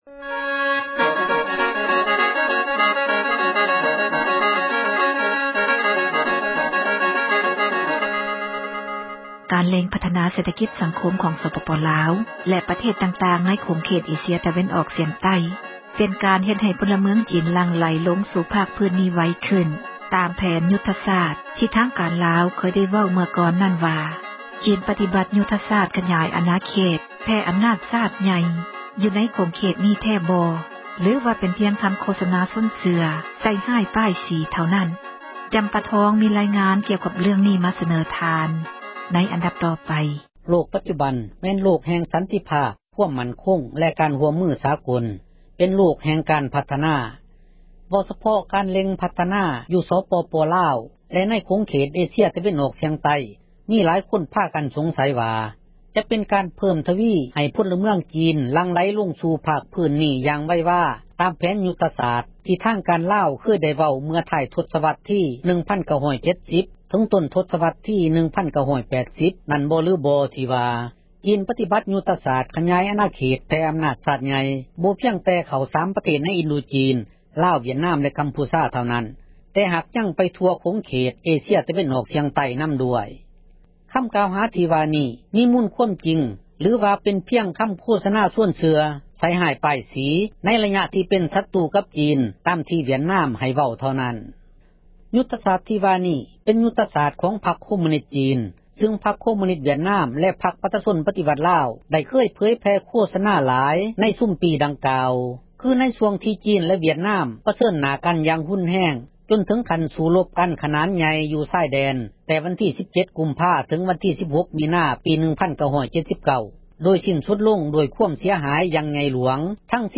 ມີລາຍງານ ກ່ຽວກັບເລື້ອງນີ້ ມາສເນີທ່ານ...